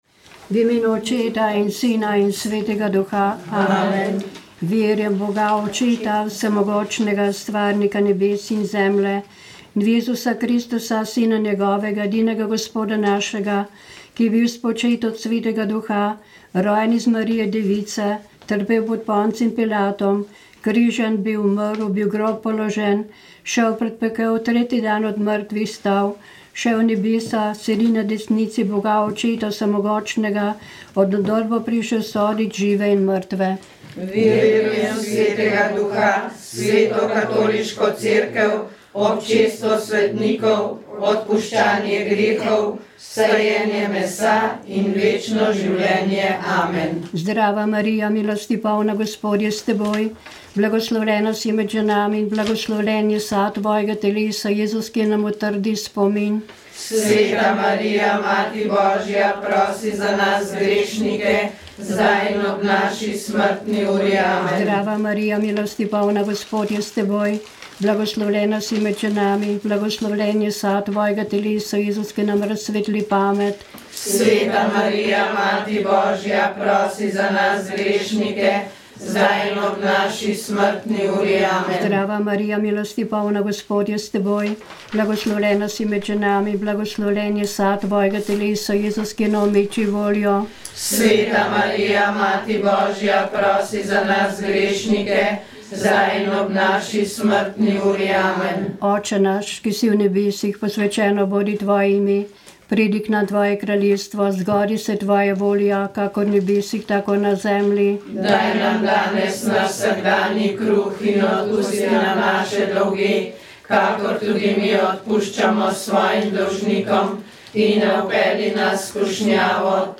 Silvestrovanje z brezdomnimi – izjava